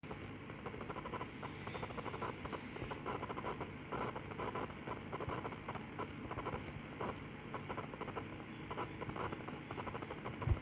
My current PC build for some reason is making the Studio monitors make a static and hissing sound, so I saw on YT people recommending to try Balanced cables.
The sound crackle is on both outputs.
Here's the sound in MP3 320 Attachments HissCrackle.mp3 HissCrackle.mp3 417.6 KB